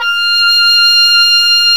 WND OBOE3 F6.wav